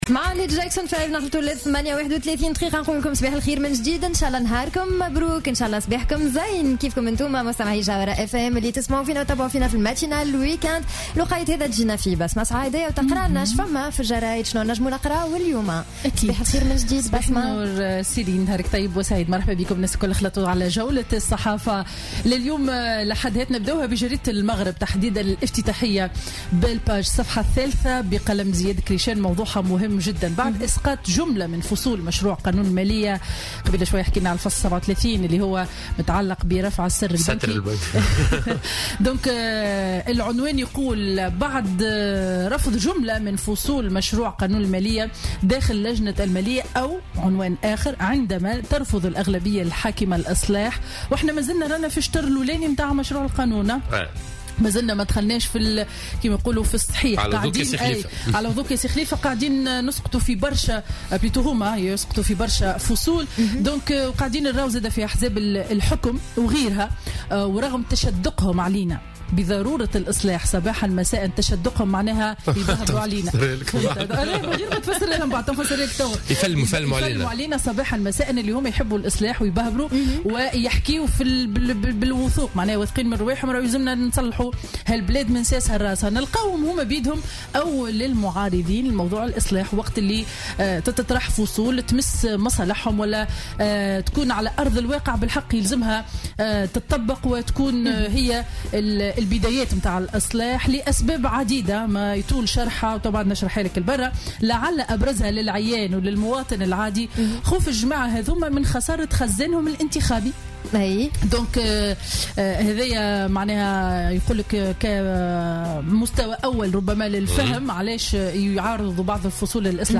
Revue de presse du dimanche 13 Novembre 2016